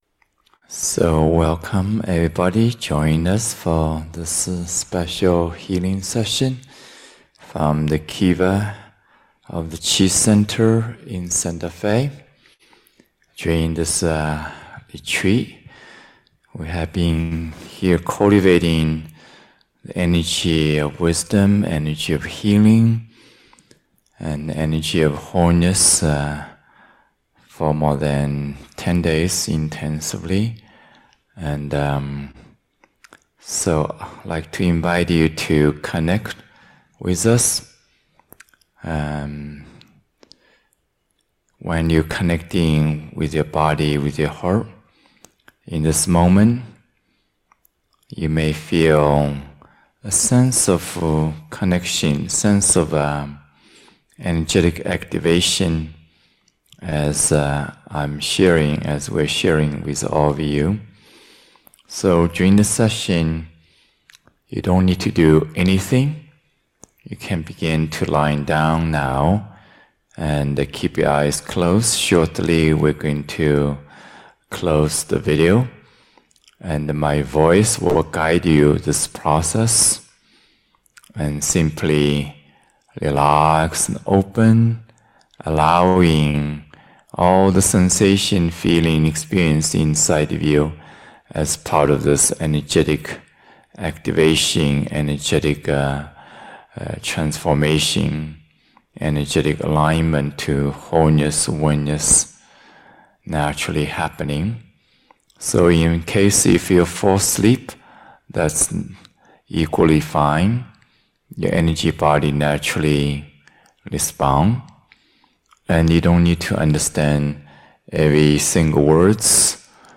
2023-10-10 Healing Session